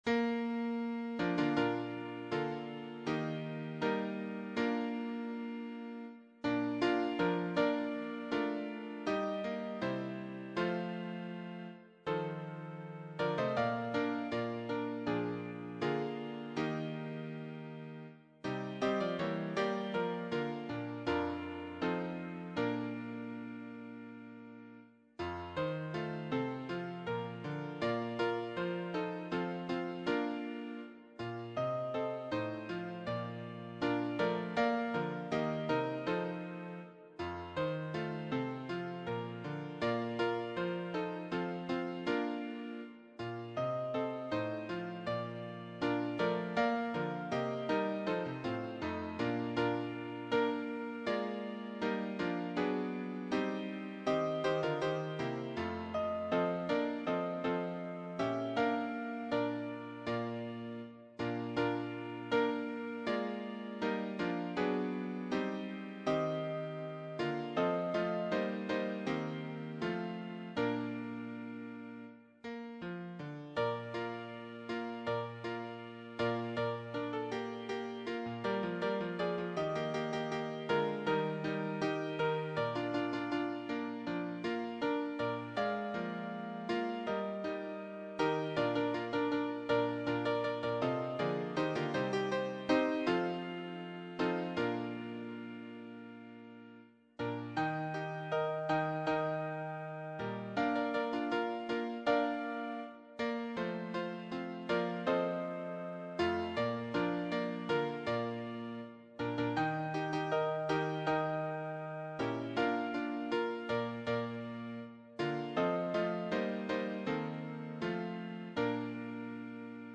choir SAATB